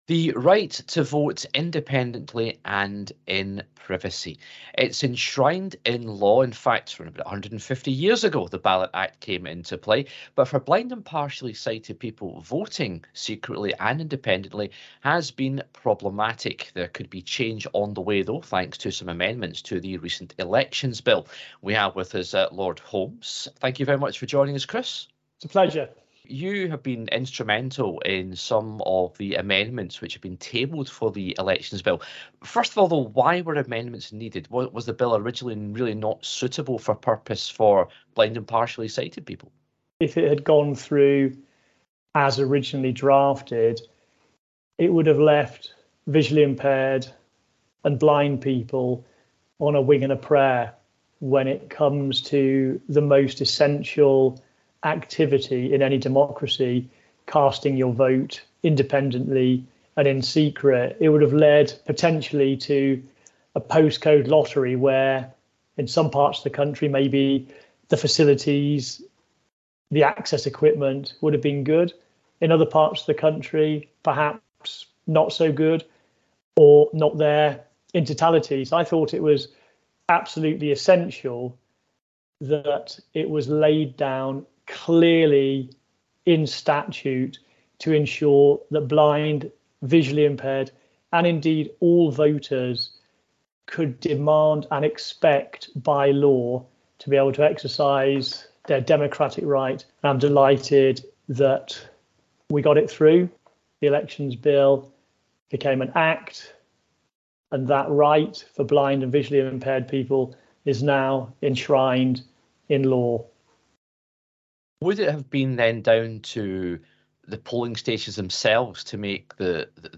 spoke to Lord Chris Holmes, who's amendments have been included in the final bill, which has received Royal assent.